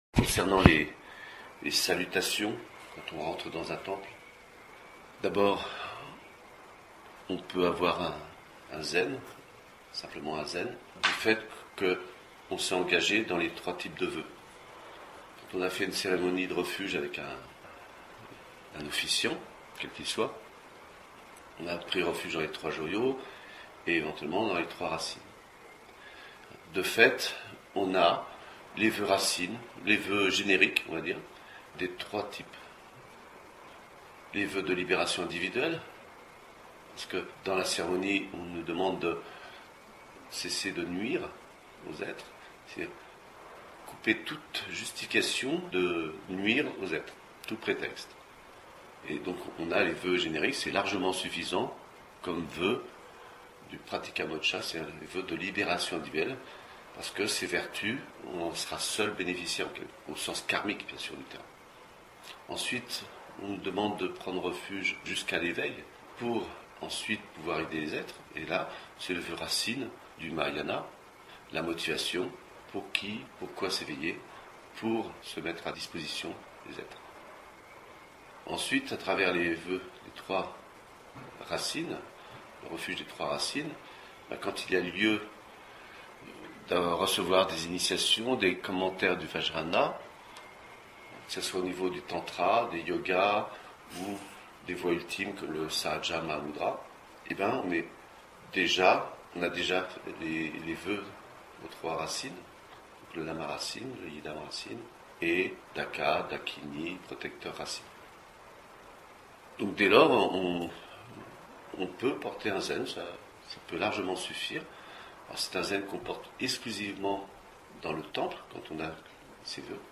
Chant des Préliminaires
Salutation dans le temple